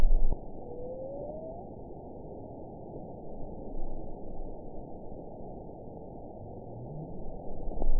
event 921837 date 12/19/24 time 18:54:23 GMT (4 months, 2 weeks ago) score 7.47 location TSS-AB06 detected by nrw target species NRW annotations +NRW Spectrogram: Frequency (kHz) vs. Time (s) audio not available .wav